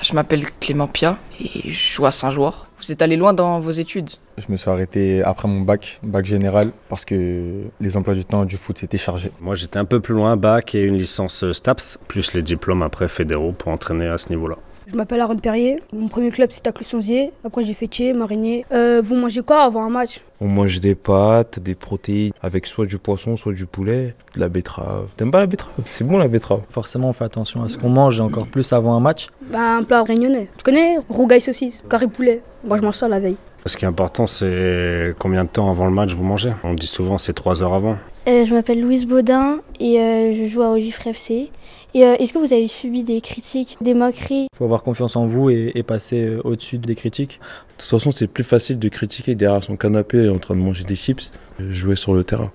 La rencontre entre les joueurs du FC Annecy et les jeunes de la Bontaz Academy s’est soldée par une grande séance de photos et de dédicaces à laquelle les jeunes du club de foot de Thyez ont aussi pu prendre part.